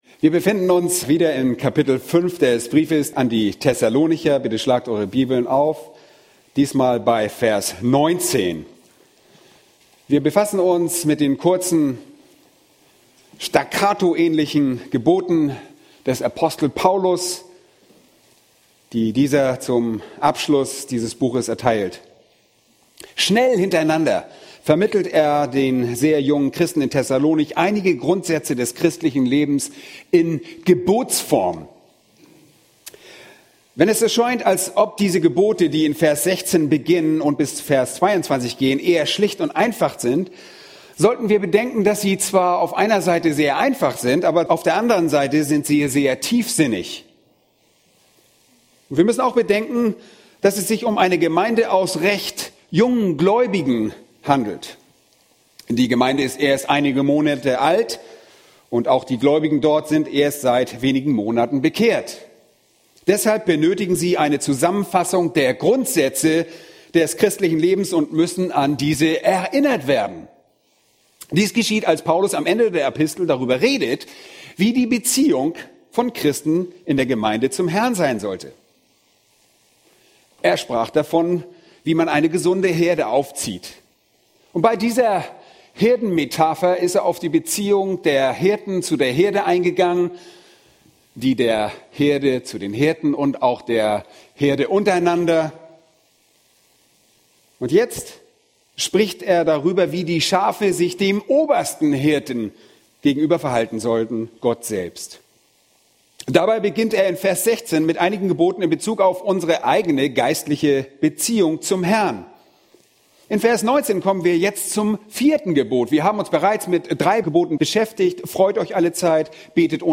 A predigt from the serie "Weitere Predigten." Hebräer 12:1-3 Wer im Glaubensleben siegen will, der muss kämpfen!